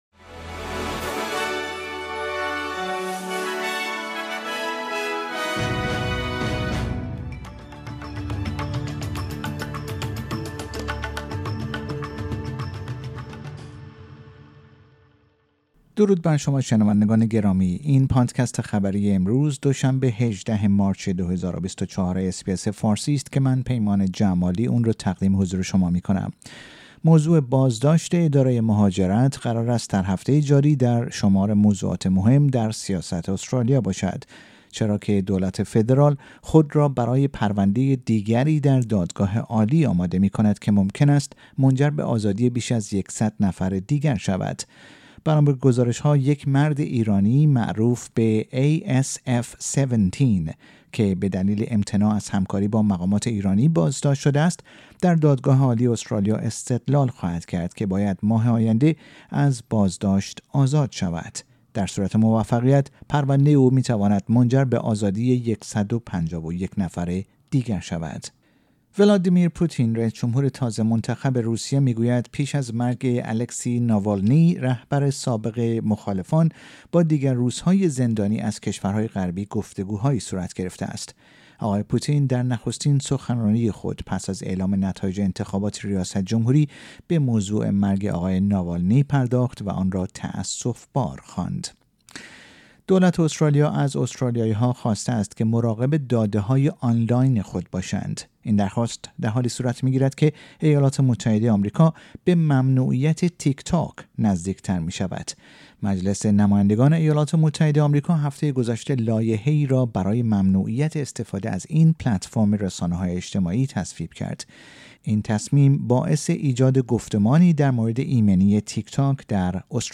در این پادکست خبری مهمترین اخبار استرالیا و جهان در روز دو شنبه ۱۸ مارچ ۲۰۲۴ ارائه شده است.